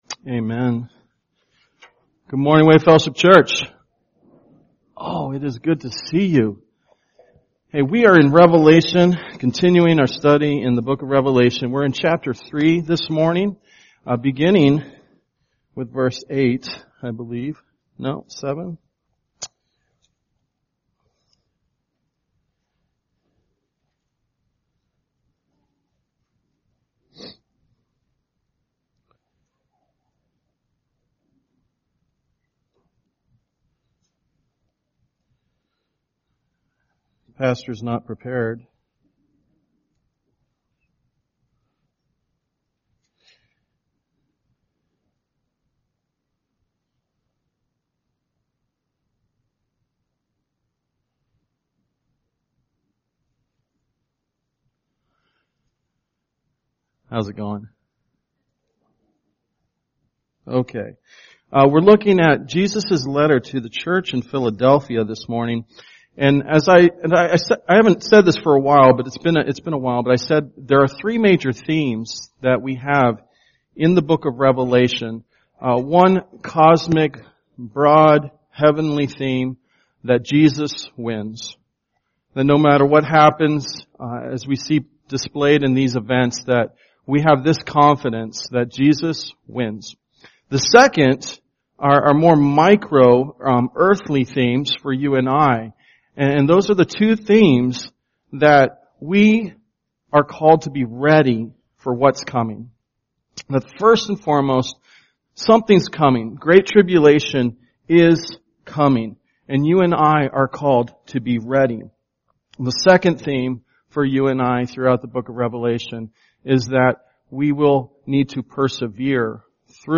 Sunday Worship
Tagged with Sunday Sermons Audio (MP3) 12 MB Previous Revelation 3:1-6: Wake Up!